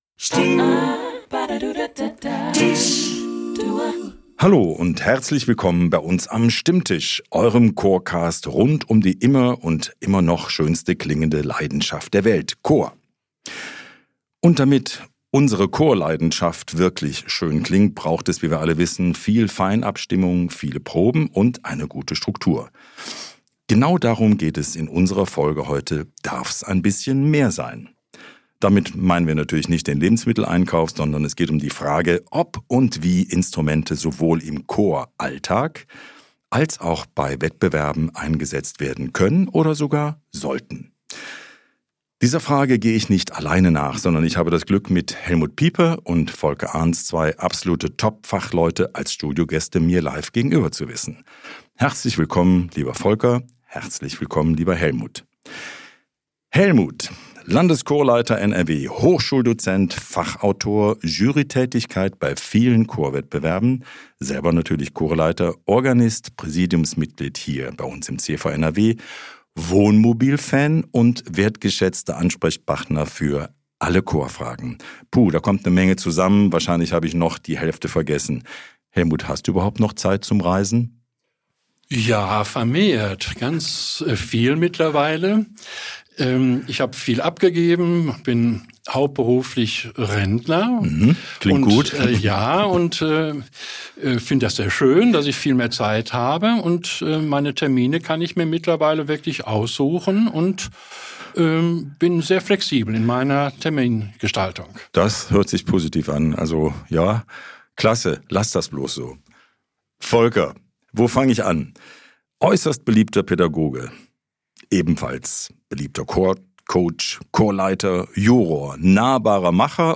Wir sprechen mit vielfältigen Gästen über gemeinsames Singen – für Gemeinschaft, Bildung und Kultur.